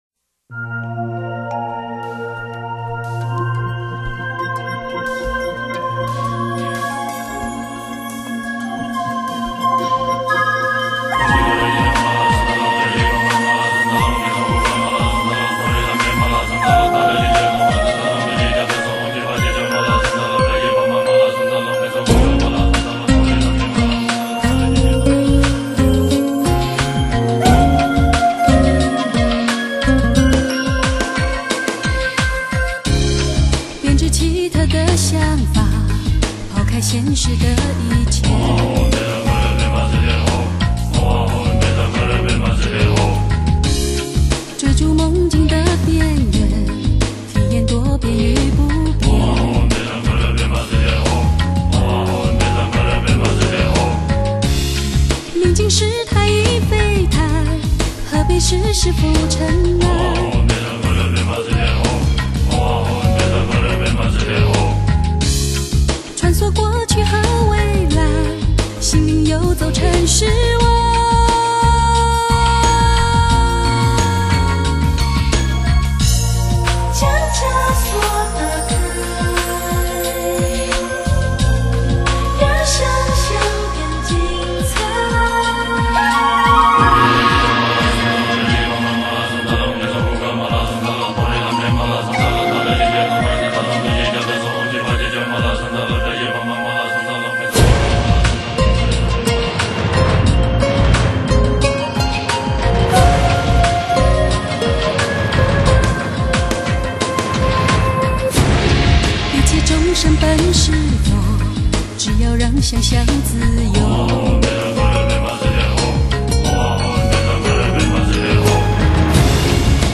沉淀于舒缓安静的音乐中
丝丝缕缕幽幽缠绵的音符